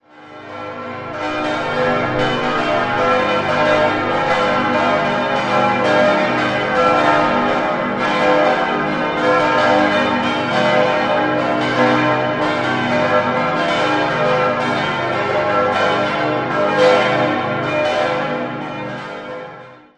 7-stimmiges Geläute: as°-c'-es'-f'-g'-b'-c'' Die Glocken wurden im Jahr 1962 von der Firma Rüetschi in Aarau hergestellt und bilden eines der umfangreichsten Geläute einer reformierten Kirche in der Schweiz.